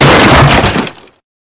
crateBreak5.ogg